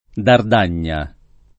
vai all'elenco alfabetico delle voci ingrandisci il carattere 100% rimpicciolisci il carattere stampa invia tramite posta elettronica codividi su Facebook Dardagna [ dard # n’n’a ] top. f. o m. — due torrenti (Piem., E.-R.)